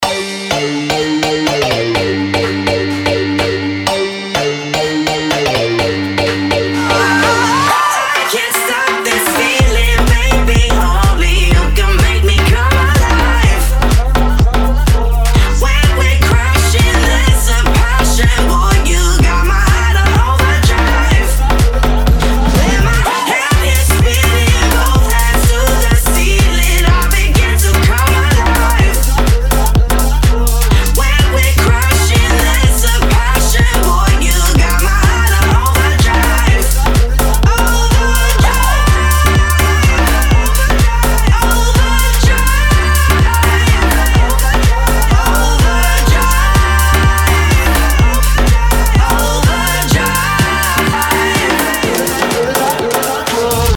future house
Jackin House